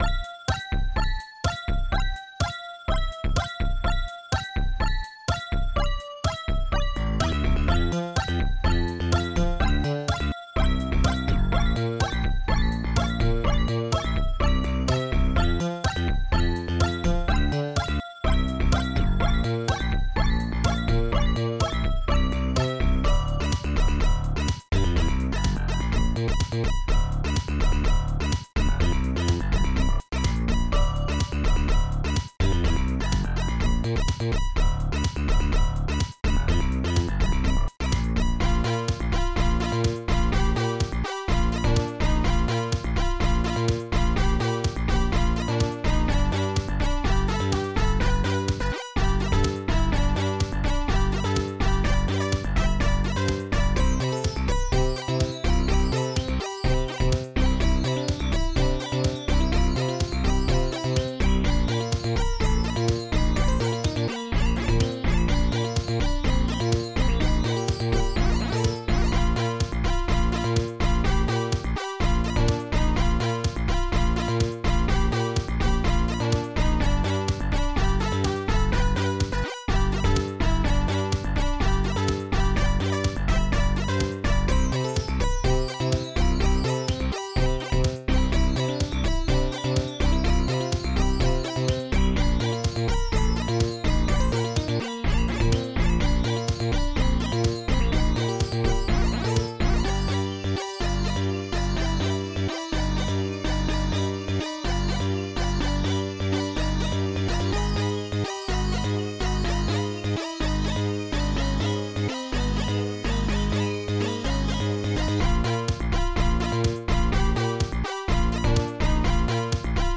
st-01:longslap
st-01:sqbrass
st-01:bassdrum5
st-01:snarebass
st-01:hihat2